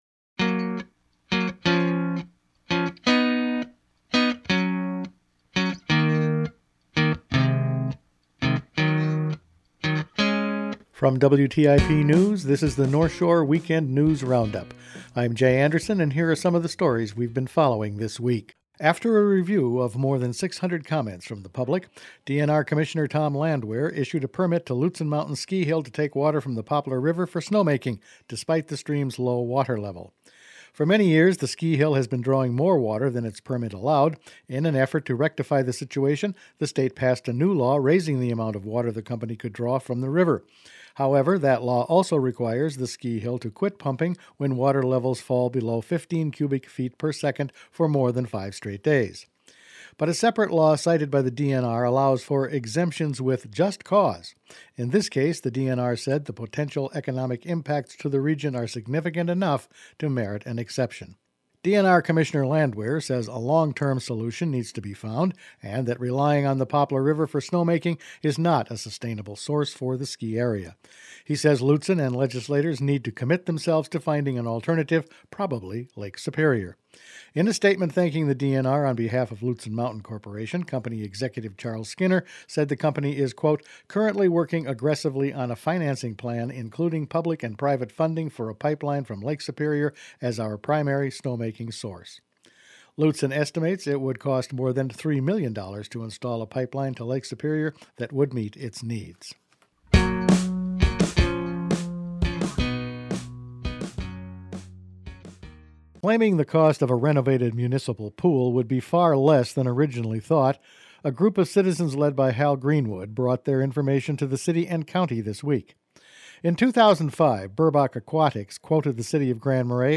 Weekend News Roundup for Nov. 12
Each weekend WTIP news produces a round up of the news stories they’ve been following this week. A new Poplar River permit for the Ski Hill, a new suggestion for the Grand Marais pool and a new local member on a Governor’s task force…all in this week’s news.